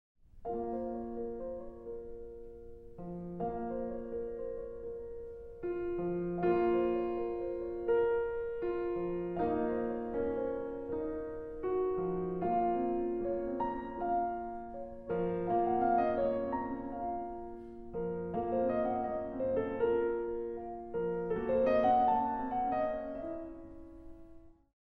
Arrangement for 2 pianos, 8 hands